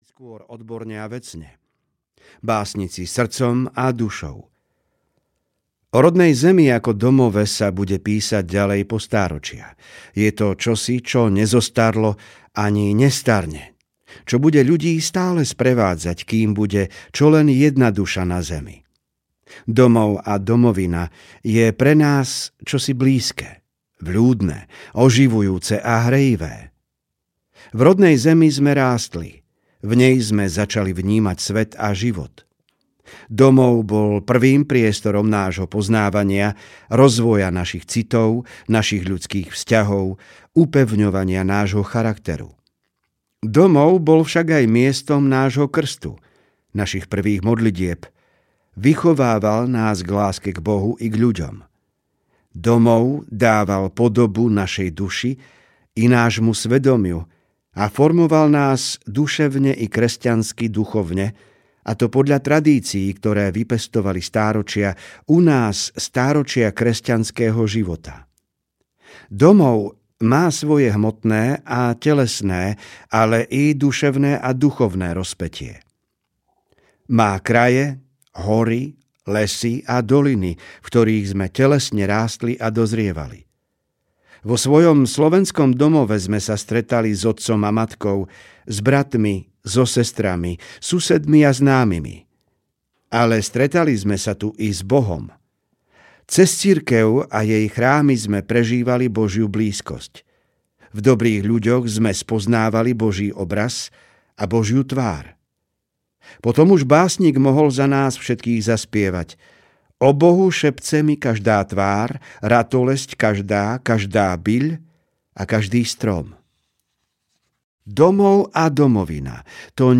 Tisíc rokov Slovenska s Cirkvou audiokniha
Ukázka z knihy